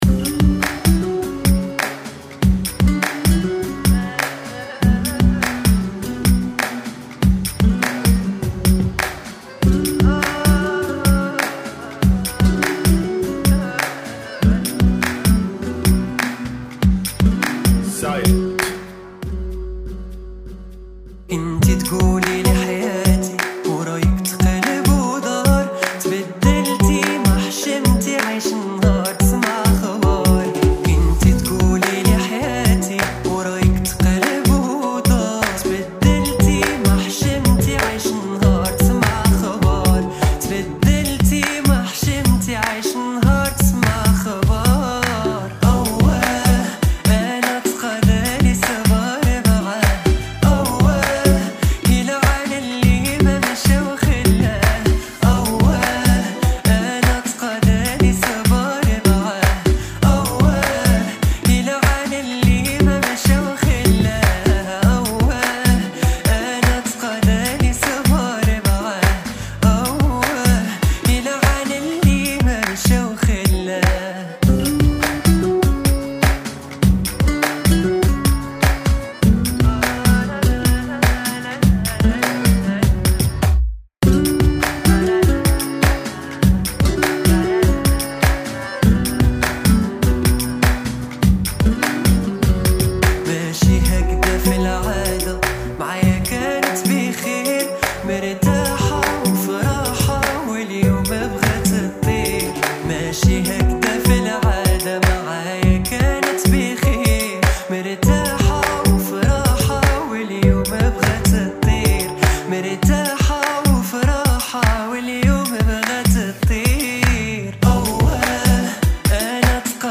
[ Bpm 100 ]